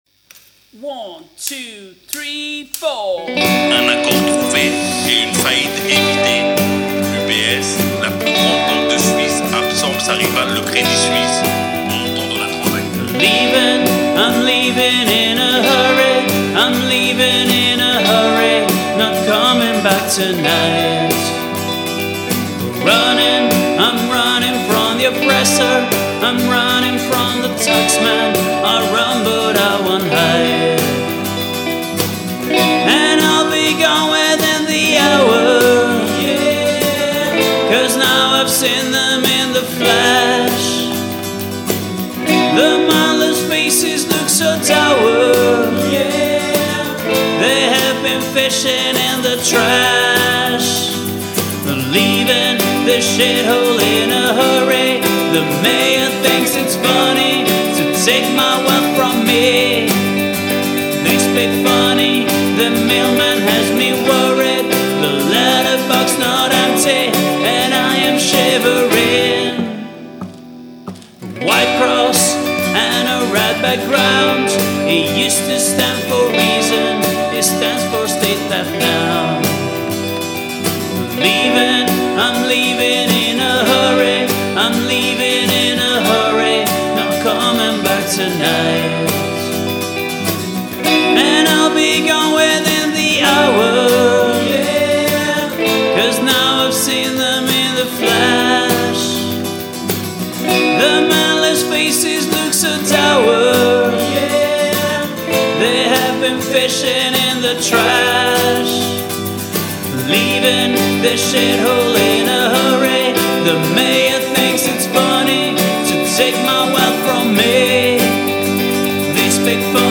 vocals, guitars, bass, drums, percussion
backing vocals